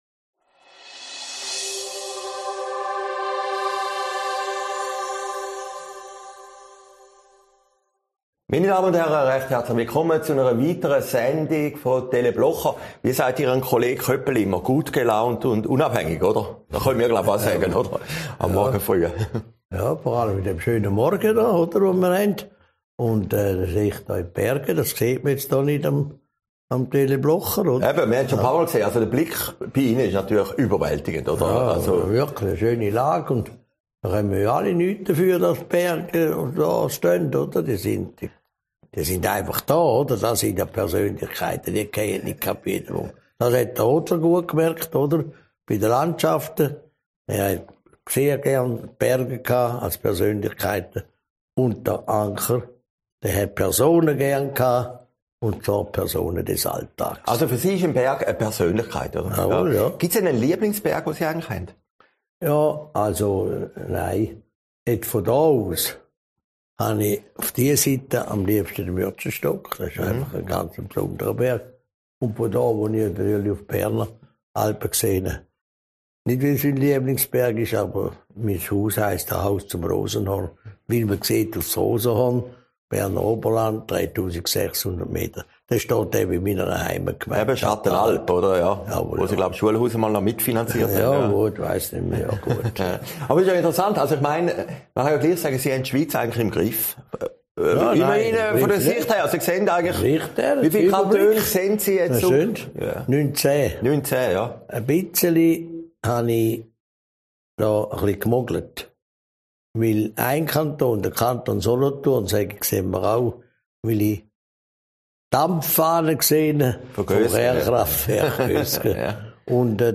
Oktober 2021, aufgezeichnet in Herrliberg